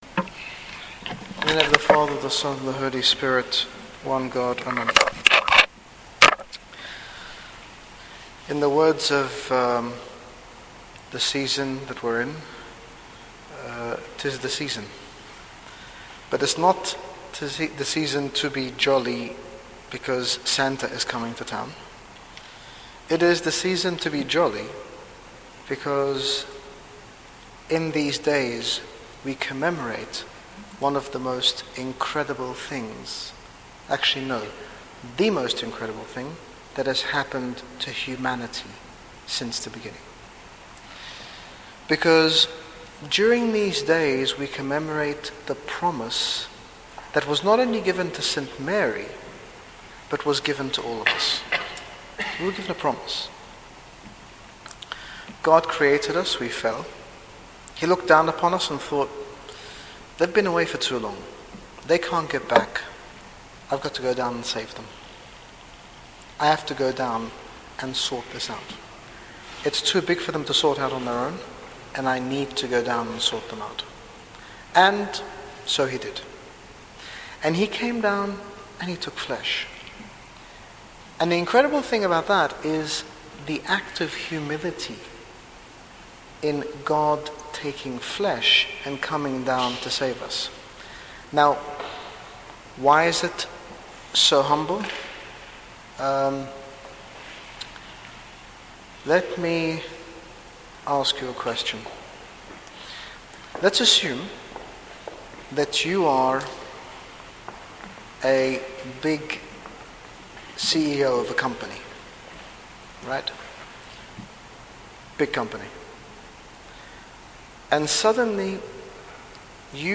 In this talk, His Grace Bishop Angaelos speaks about St. Mary’s troubled reaction to the annunciation. His Grace emphasises the importance of asking questions through prayer during times of confusion. He explains that we must wait upon God as those who wait for the morning because He will reveal all His promises us in the fullness of time, and He can and will do the impossible.